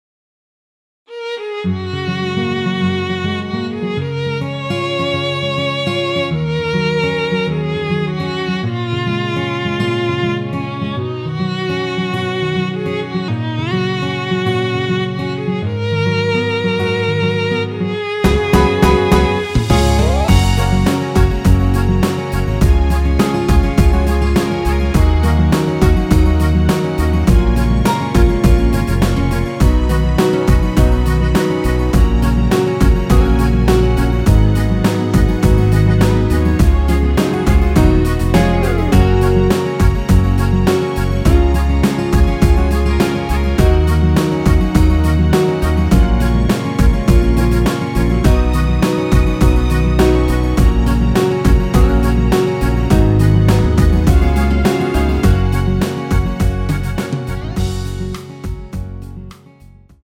F#
앞부분30초, 뒷부분30초씩 편집해서 올려 드리고 있습니다.
중간에 음이 끈어지고 다시 나오는 이유는